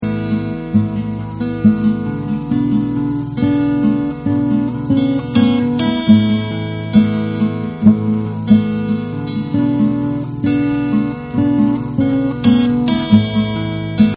All four tracks are acoustic guitar.
Here's a short excerpt of my recording with a static mix.
While that's a fair representation of my sloppy musicianship, I'd like to liven it up a bit.
This sounds pretty stinking good considering it was recorded on a low-end four-track cassette recorder in my apartment living room 30 years ago.